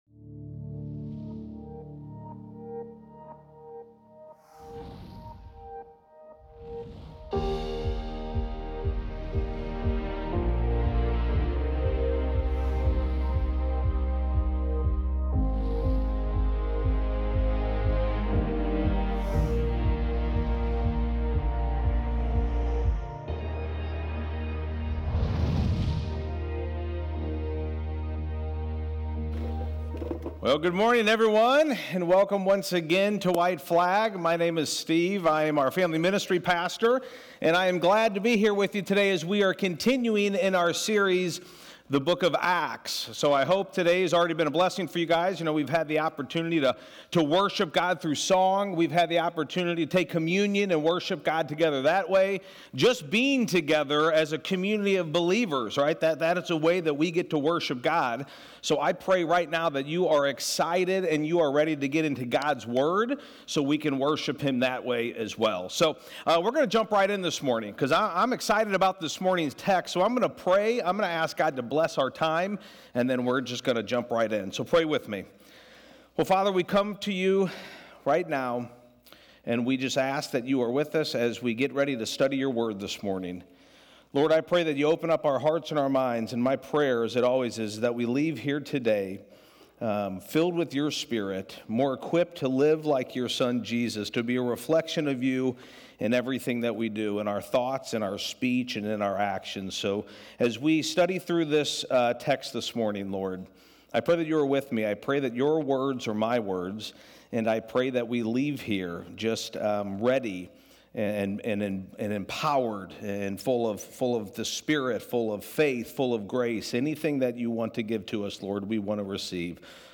acts-6-8-15-sermon-audio.mp3